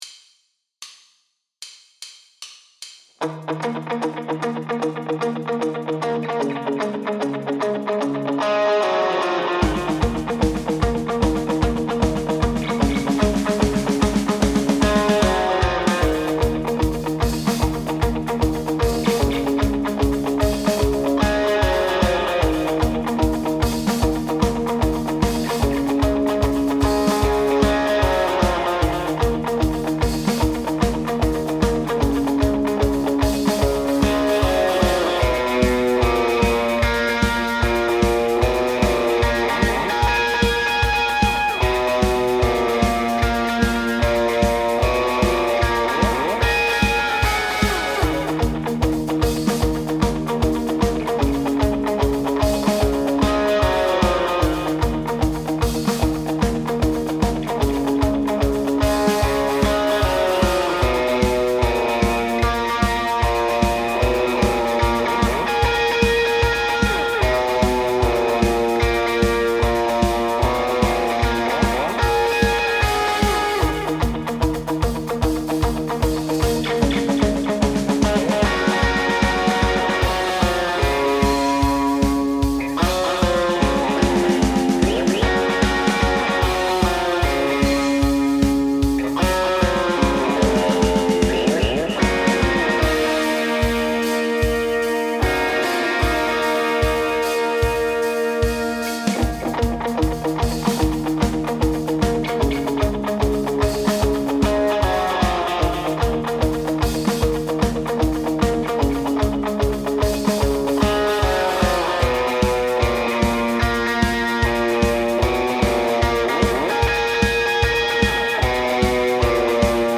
Without vocals
Based on the album and rare live version